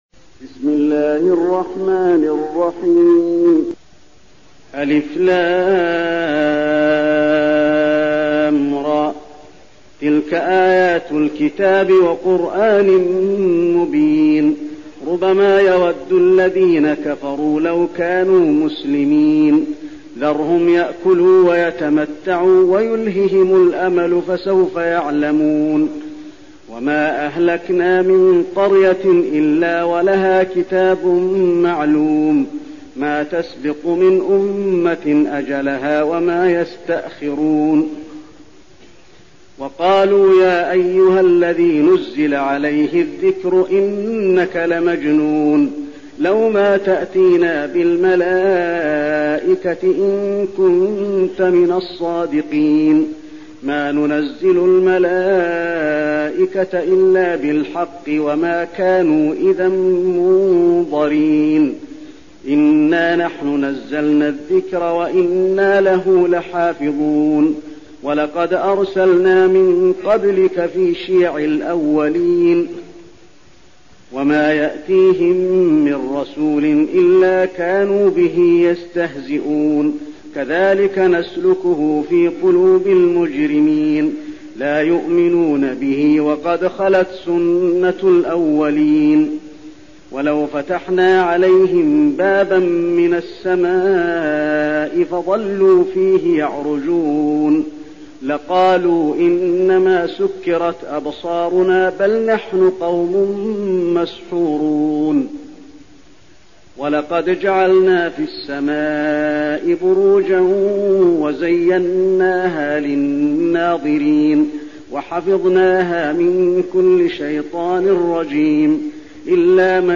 المكان: المسجد النبوي الحجر